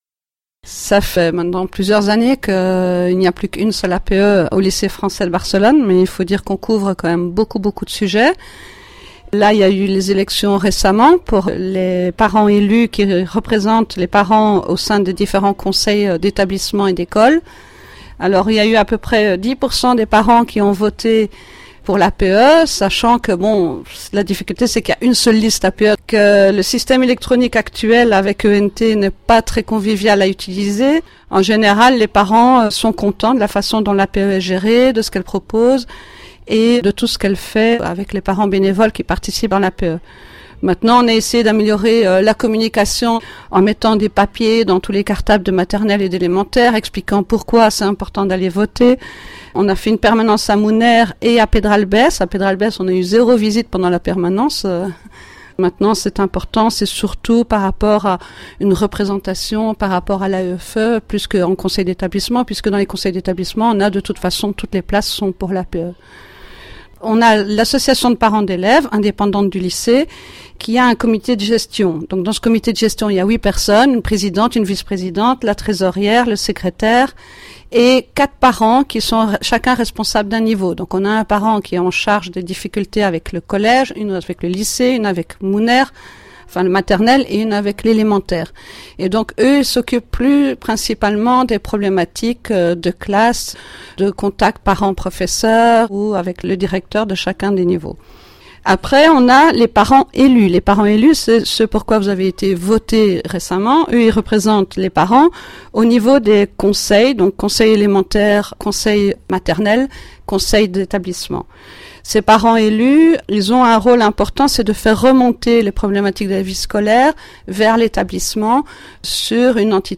14/11/2017 à 09h 30| 5 mn | interview| lycée | événement |télécharger